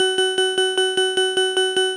pacextralife.wav